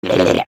Minecraft Version Minecraft Version 25w18a Latest Release | Latest Snapshot 25w18a / assets / minecraft / sounds / entity / shulker / ambient6.ogg Compare With Compare With Latest Release | Latest Snapshot